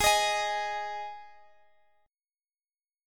G#5 chord